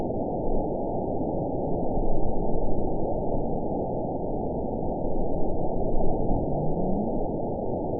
event 912599 date 03/30/22 time 00:41:44 GMT (3 years, 1 month ago) score 9.54 location TSS-AB01 detected by nrw target species NRW annotations +NRW Spectrogram: Frequency (kHz) vs. Time (s) audio not available .wav